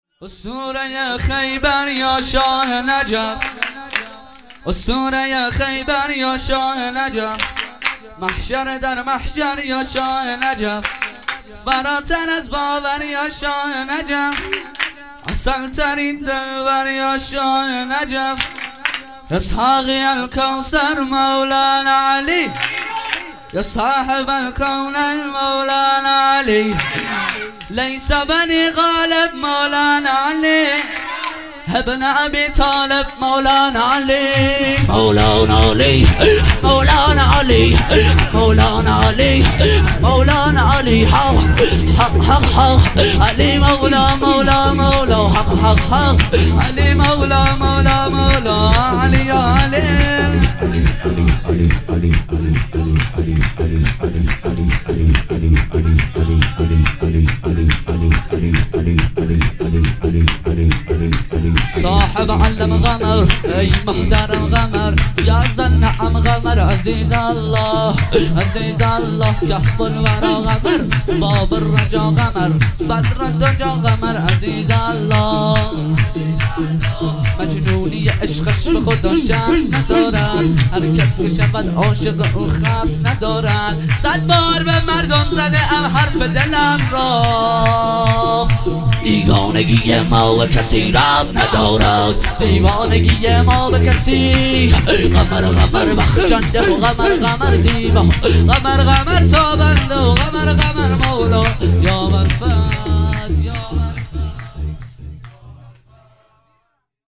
جشن ولادت حضرت زهرا سلام الله ۲۴-۱۱-۹۸